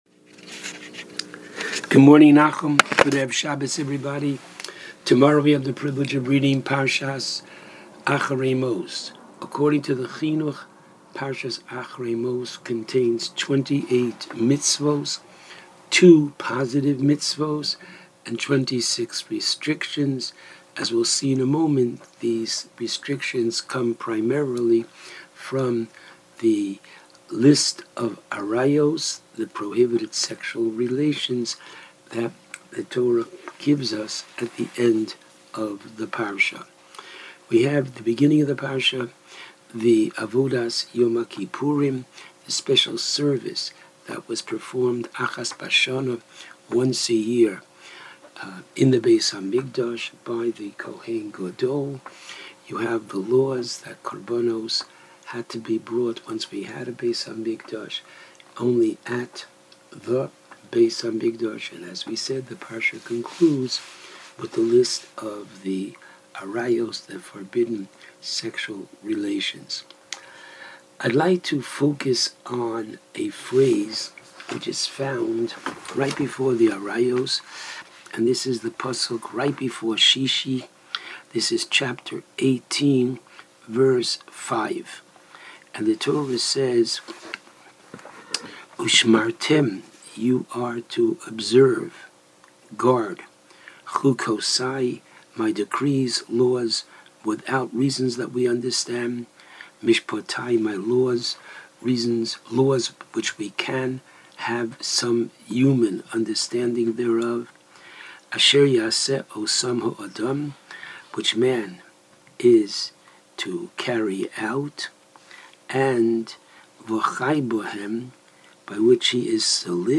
called in to JM in the AM to discuss Parshas Acharei Mos.